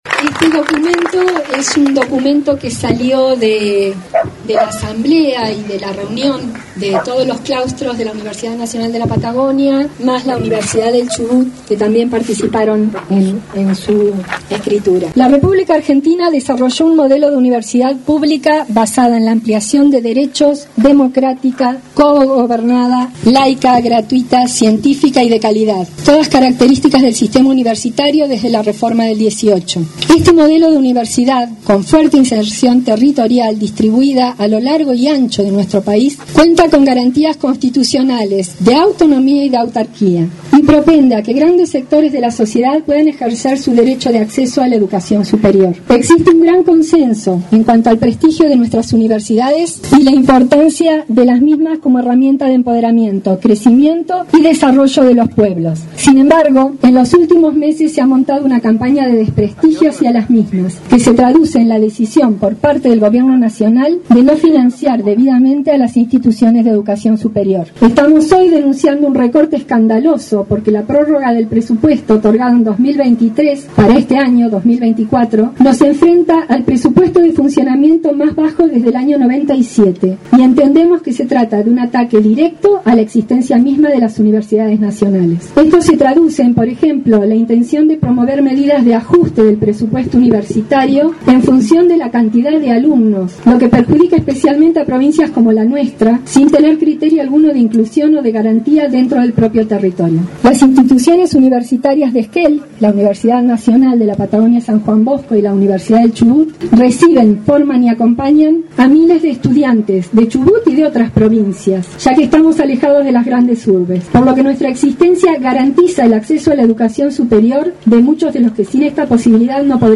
Luego se dio lectura al documento de la marcha.